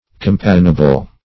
Meaning of compassionable. compassionable synonyms, pronunciation, spelling and more from Free Dictionary.